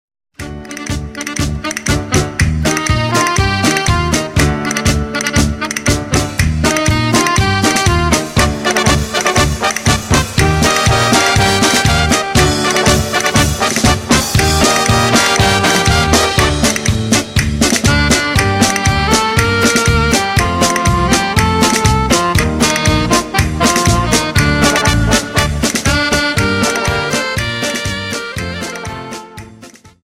Dance: Paso Doble 60 Song